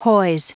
Prononciation du mot hoise en anglais (fichier audio)
Prononciation du mot : hoise